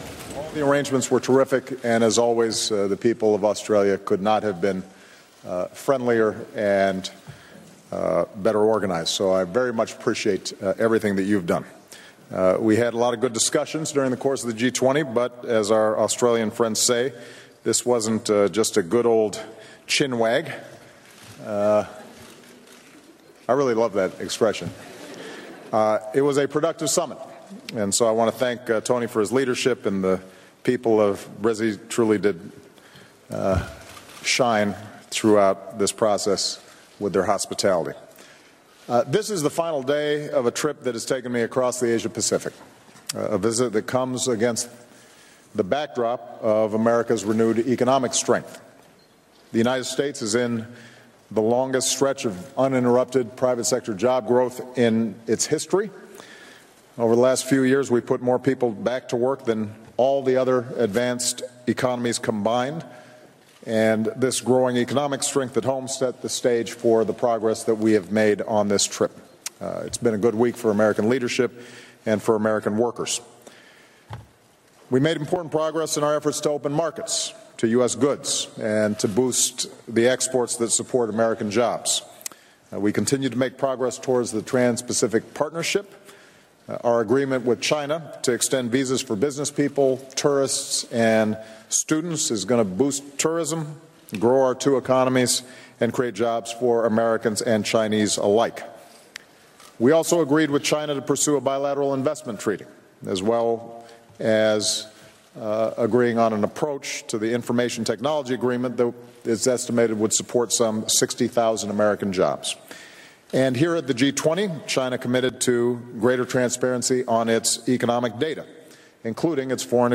Final G20 Brisbane 2014 press conference